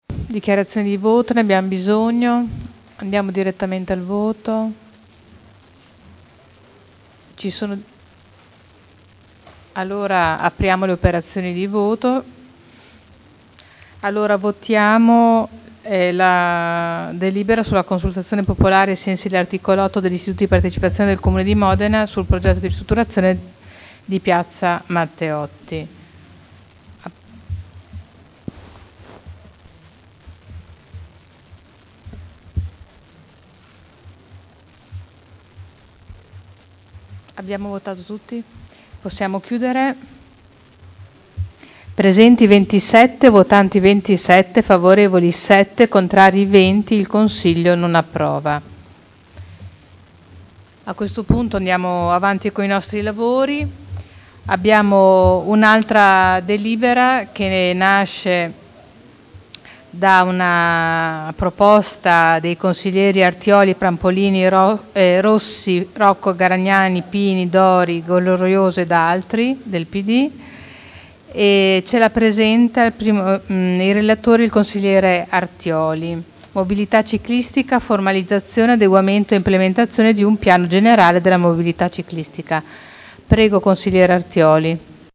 Presidente — Sito Audio Consiglio Comunale
Seduta del 21/07/2011. Mette ai voti la delibera: Consultazione popolare ai sensi dell’art. 8 degli istituti di partecipazione del Comune di Modena sul progetto di ristrutturazione di Piazza Matteotti (Conferenza Capigruppo del 27 giugno 2011 e del 4 luglio 2011).